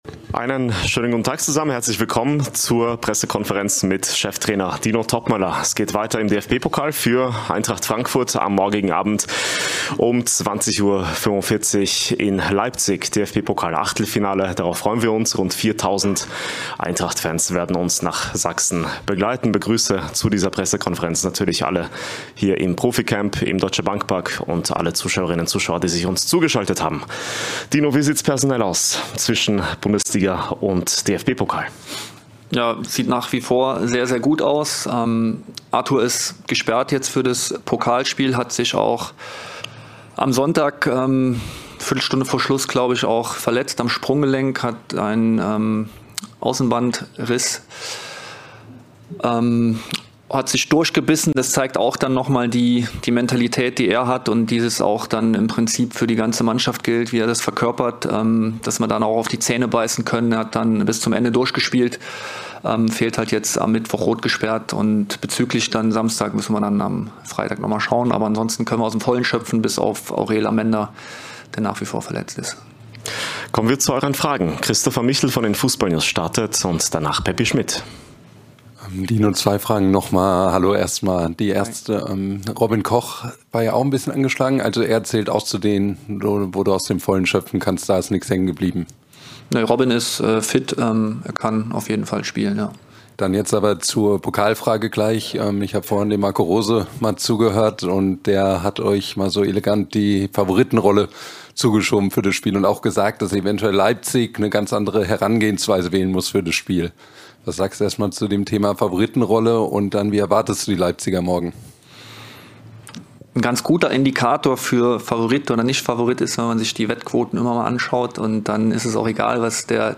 Die Pressekonferenz mit Cheftrainer Dino Toppmöller vor dem DFB-Pokal-Auswärtsspiel in Leipzig.